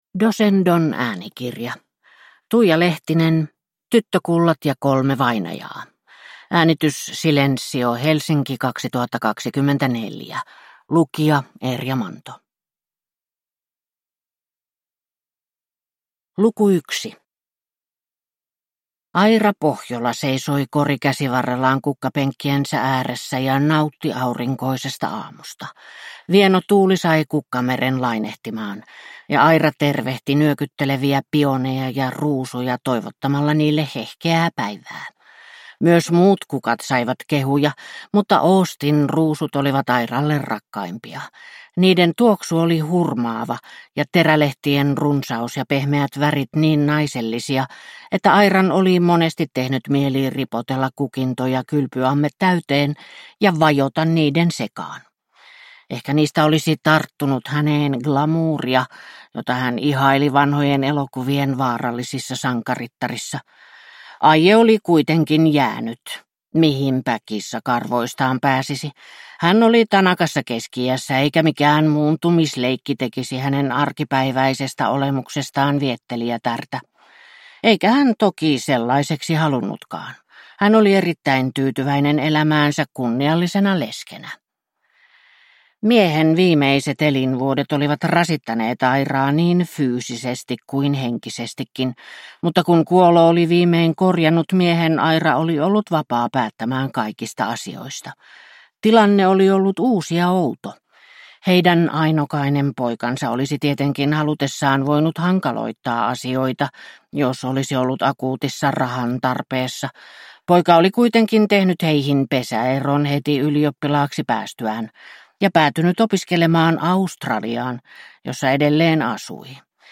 Tyttökullat ja kolme vainajaa (ljudbok) av Tuija Lehtinen